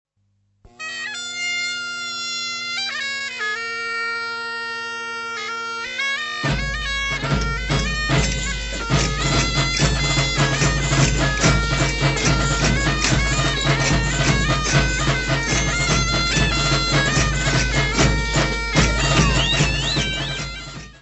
Folclore português : Trás-os-Montes e Alto Douro
Grupo Folclórico Mirandês de Duas Igrejas
Repasseado clássico (Baile do Terreiro).